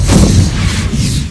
scanner
cbot_discharge3.ogg